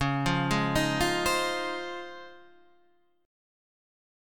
C# Major 9th